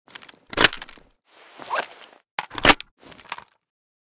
aug_reload.ogg.bak